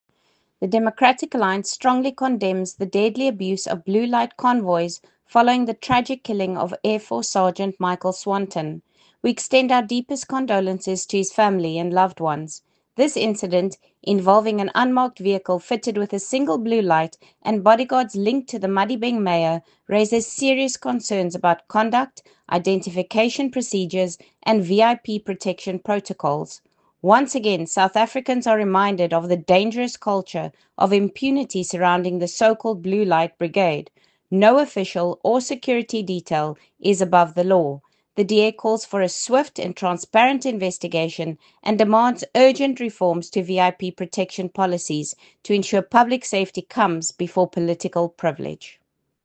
Afrikaans soundbite by Lisa Schickerling MP